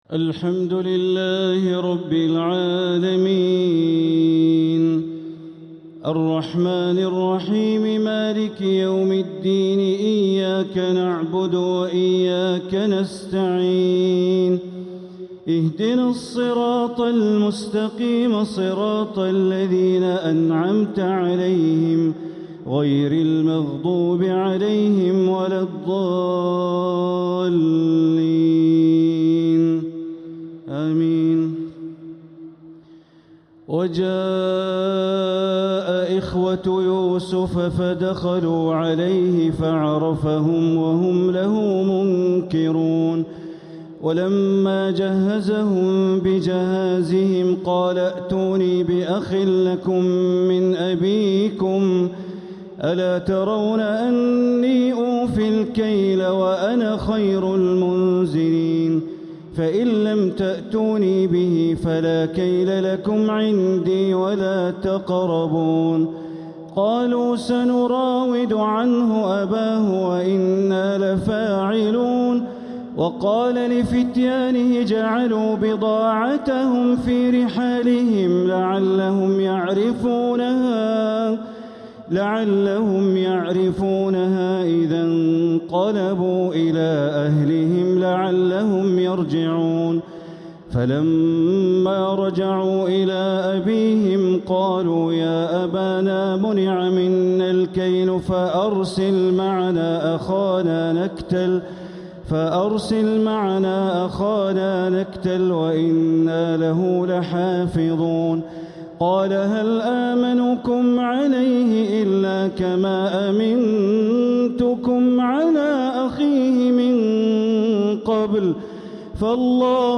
تراويح ليلة 17رمضان 1447هـ من سورتي يوسف (58) والرعد (1-18) | Taraweeh 17th night Ramadan 1447H Surah Yusuf and Al-raad > تراويح الحرم المكي عام 1447 🕋 > التراويح - تلاوات الحرمين